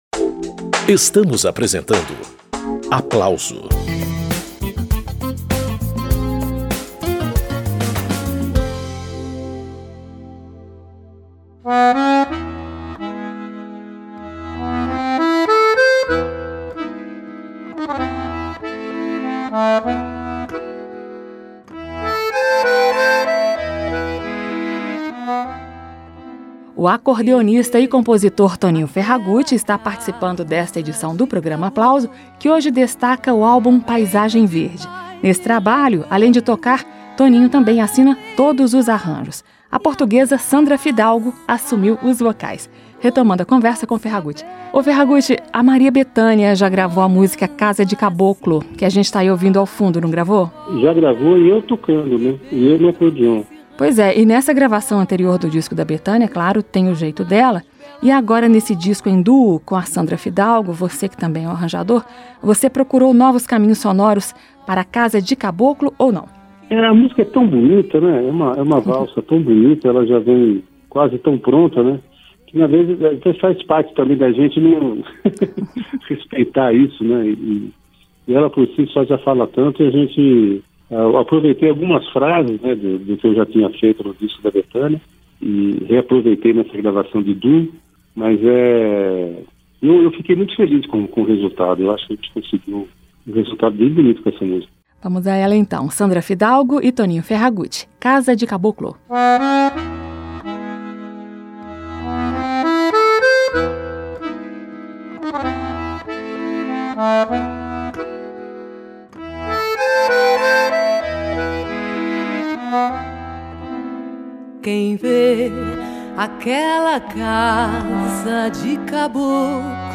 Voz e acordeom embalam canções de Brasil e Portugal - Rádio Câmara